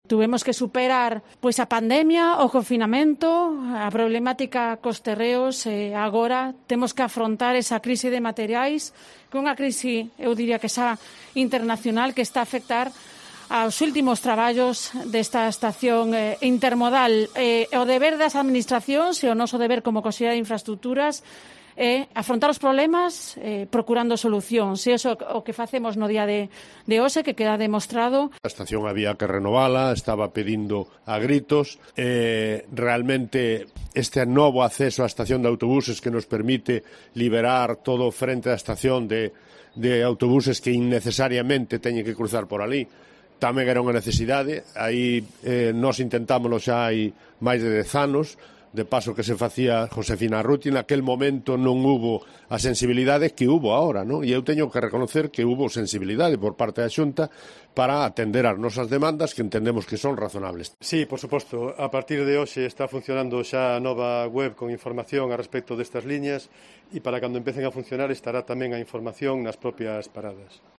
AUDIO: Conselleira, alcalde y concejal de Movilidad hablan sobre transporte en autobús en Pontevedra